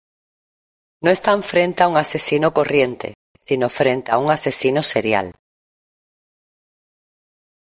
Uitgesproken als (IPA)
/seˈɾjal/